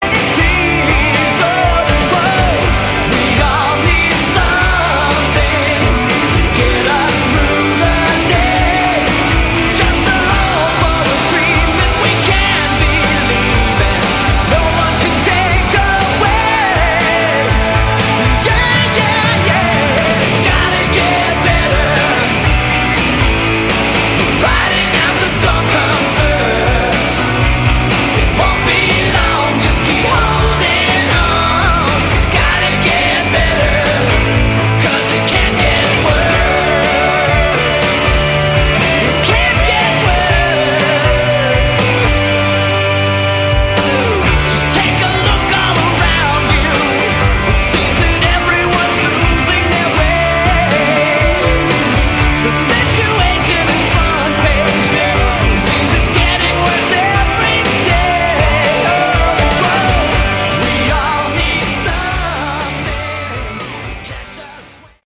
Category: AOR
Original demos.